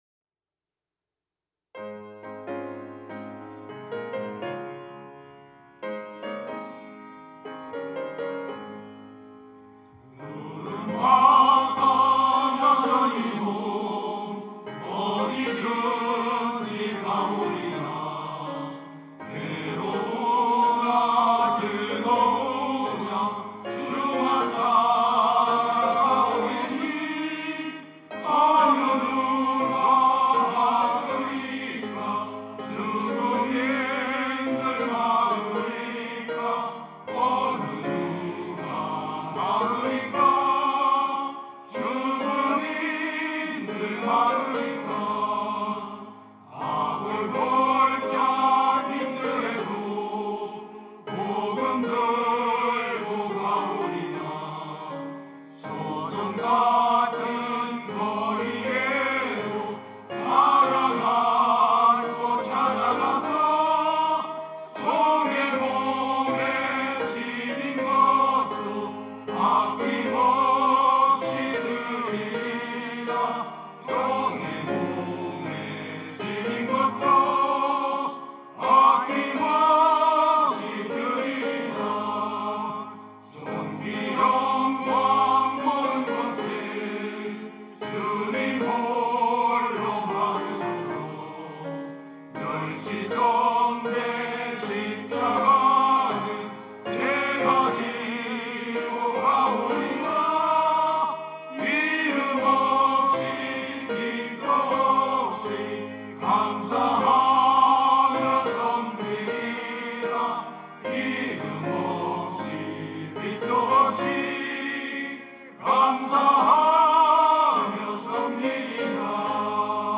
특송과 특주 - 부름 받아 나선 이 몸
남선교회 임원